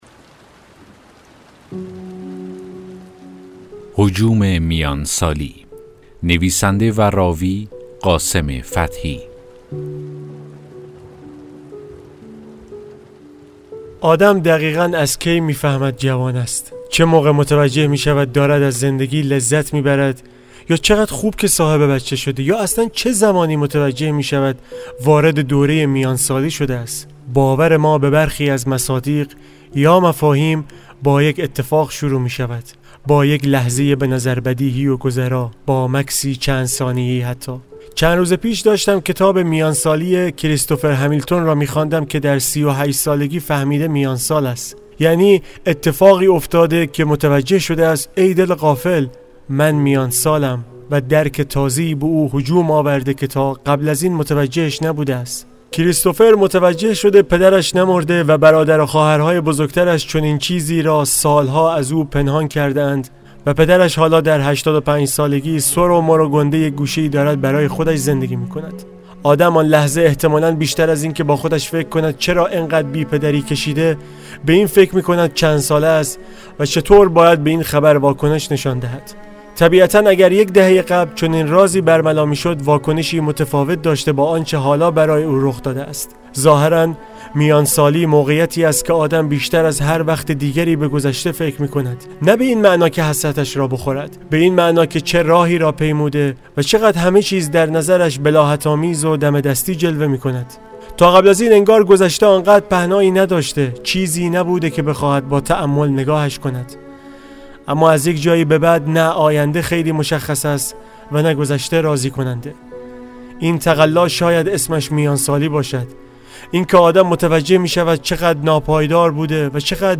داستان صوتی: هجوم میان سالی